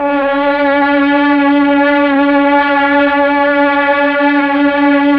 Index of /90_sSampleCDs/Roland LCDP09 Keys of the 60s and 70s 1/STR_Melo.Strings/STR_Tron Strings